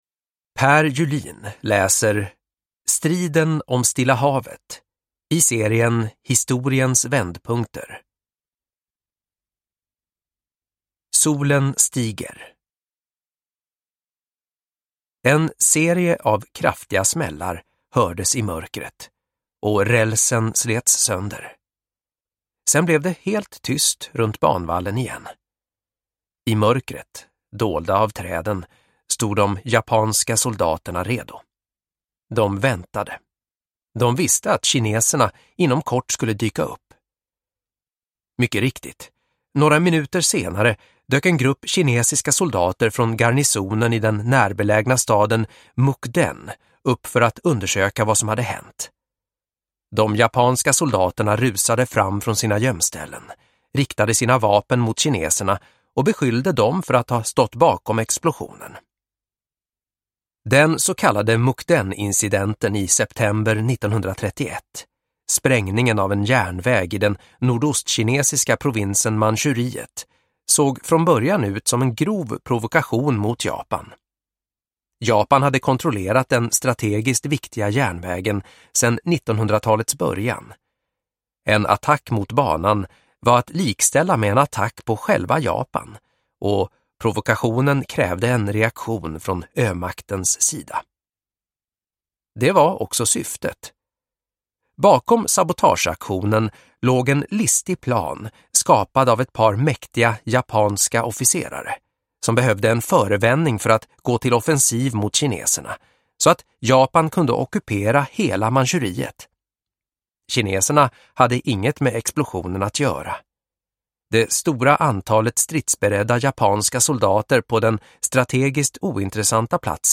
Striden om Stilla havet – Ljudbok – Laddas ner